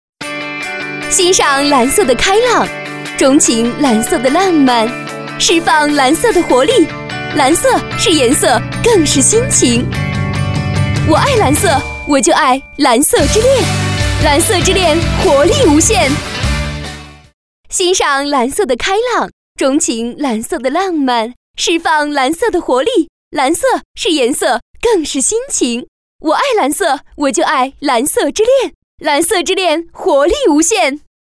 女国170_广告_品牌形象_蓝色之恋_欢快.mp3